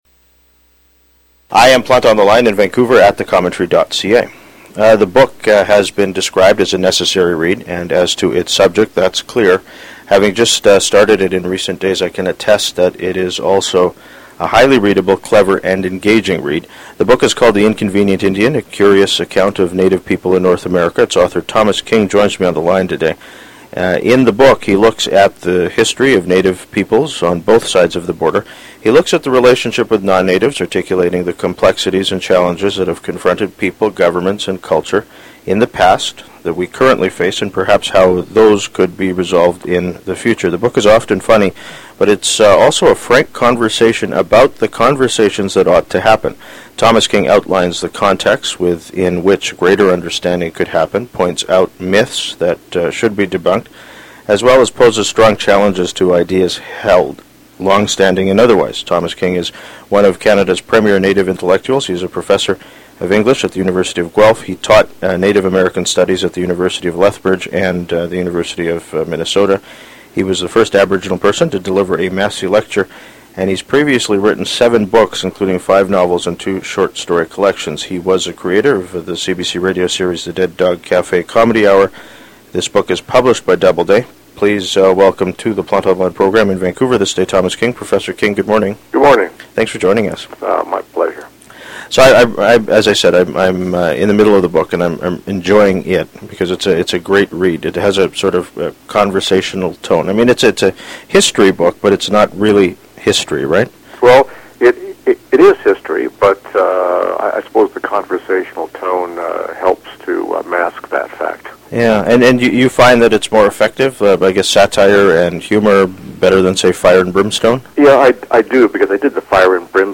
Its author Thomas King joins me on the line today. In the book he looks at the history of Native peoples on both sides of the border.